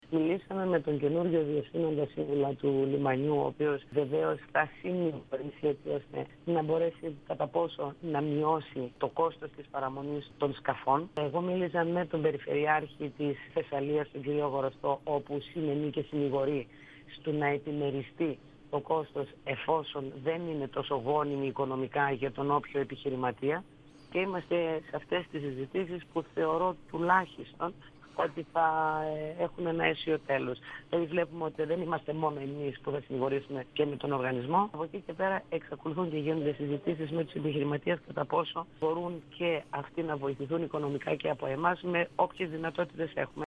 H αντιπεριφερειάρχης Θεσσαλονίκης, Βούλα Πατουλίδου, στον 102FM του Ρ.Σ.Μ. της ΕΡΤ3
Συνέντευξη